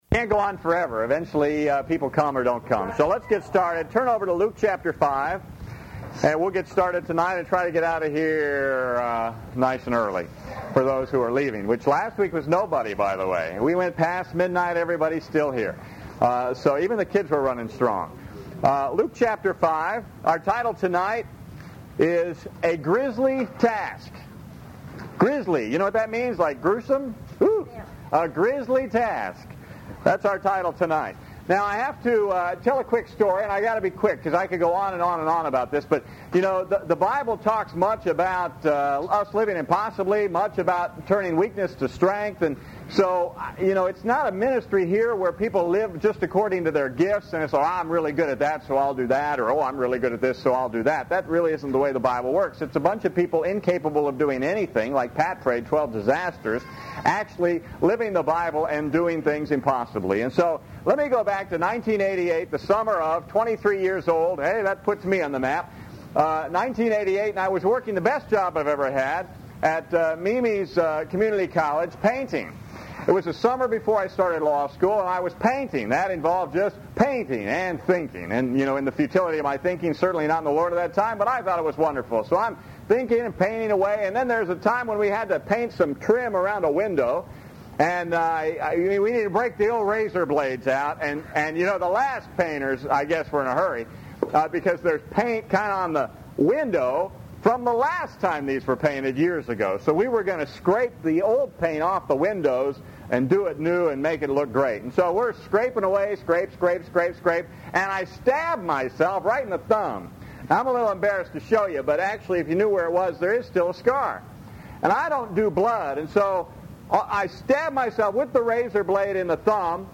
This message features the reading and preaching of the following Scriptures: Luke 5:30-32 Luke 8:1-3, 26-29, 34-37 Jude :22-23 Instructions: To download on a Mac, control-click the message link below and select a download option.